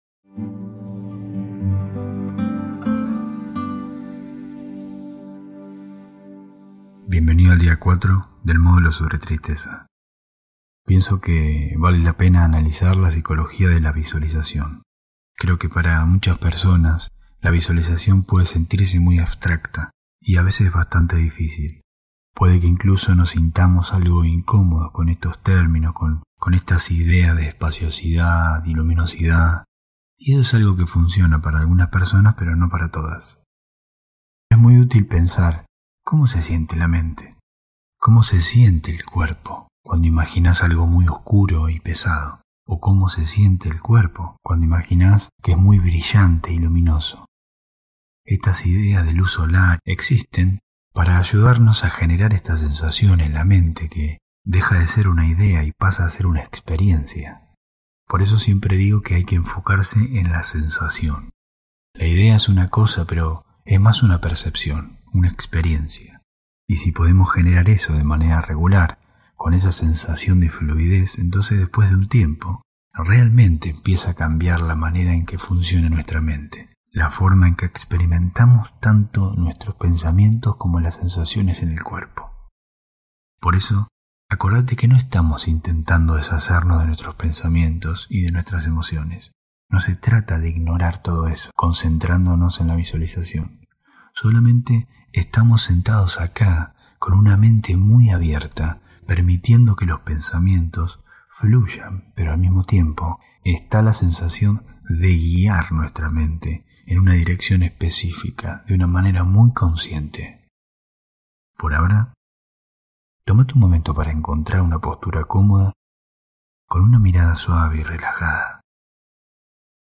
Cambiá la forma de relacionarte con la tristeza. Día 4 [Audio 8D. Mejor con auriculares] Hosted on Acast.